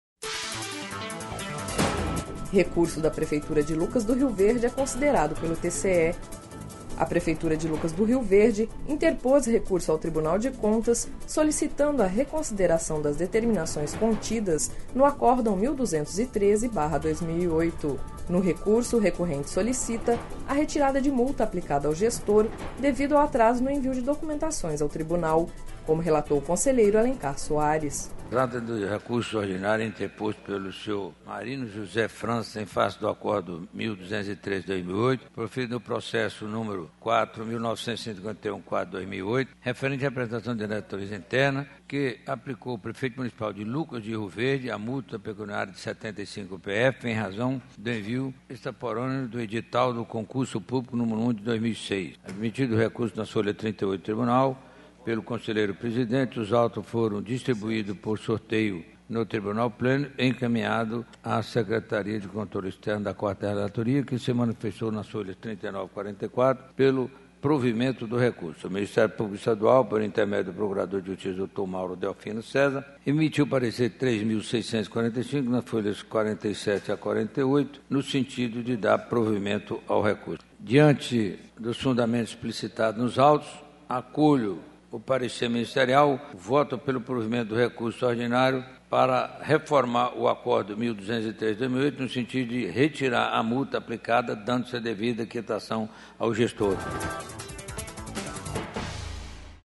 Sonora: Alencar Soares – conselheiro do TCE-MT